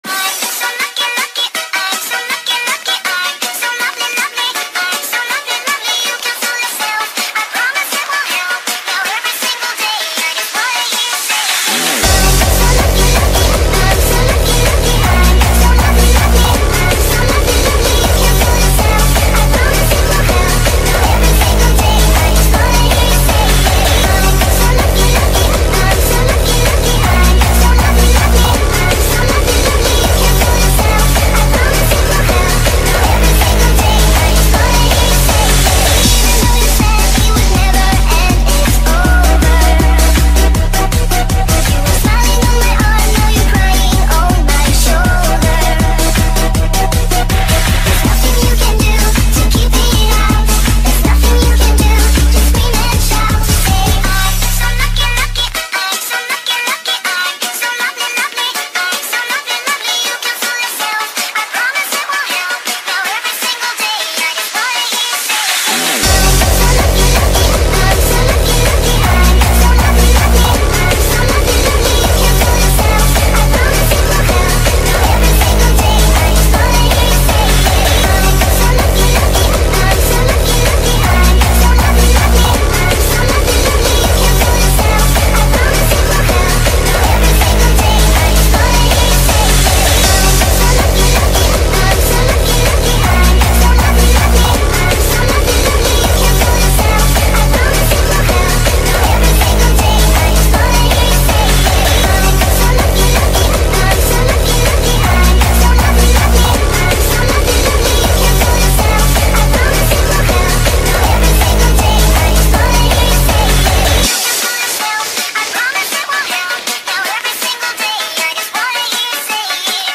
ورژن hardstyle
فانک